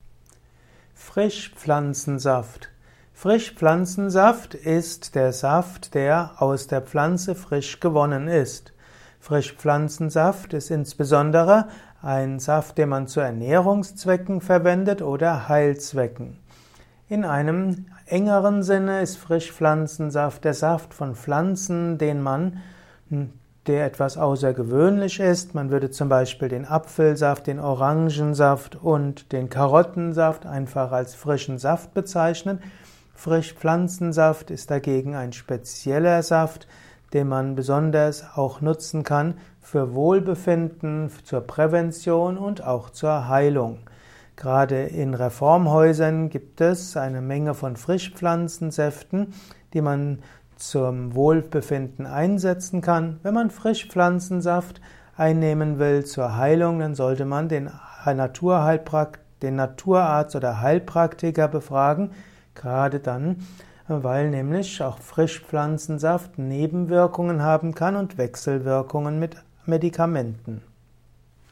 Ein Kurzvortrag über Frischpflanzensaft